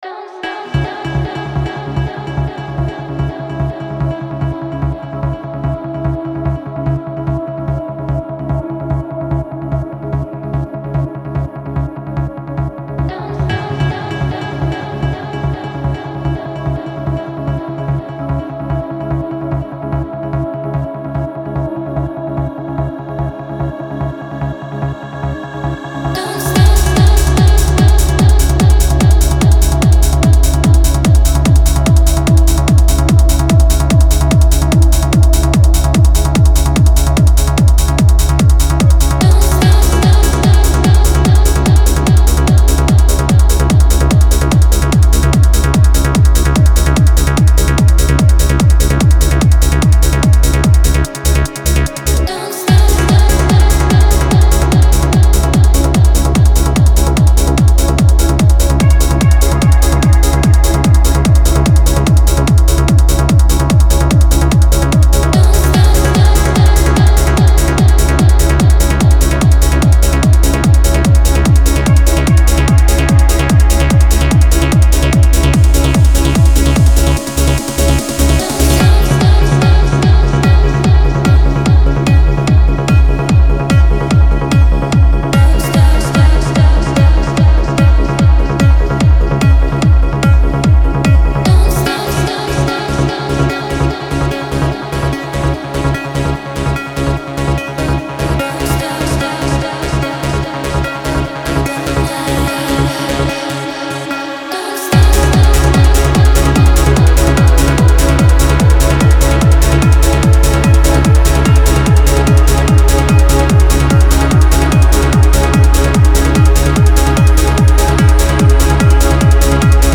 • Жанр: Trance